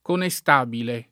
conestabile [ kone S t # bile ] o connestabile [ konne S t # bile ] o contestabile [ konte S t # bile ] s. m. (stor.)